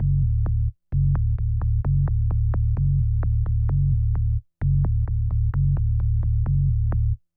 bass01.wav